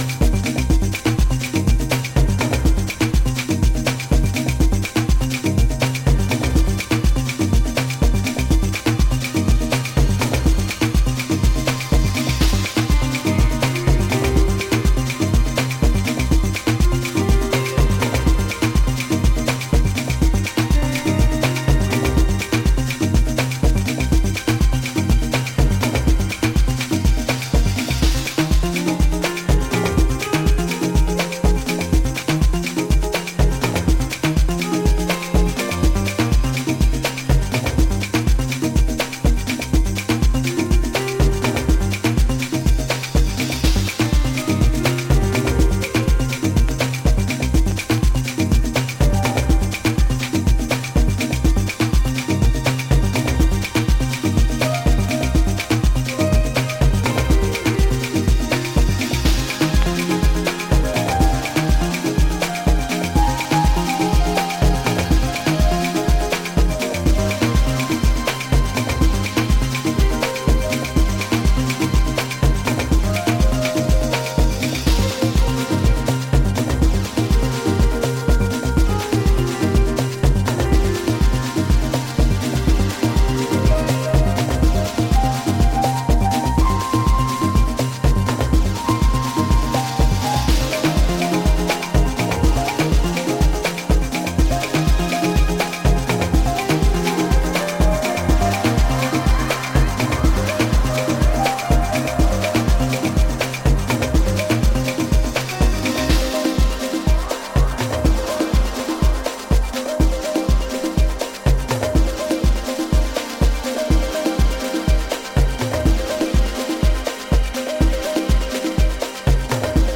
Afro Latin and deep house
soul-stirring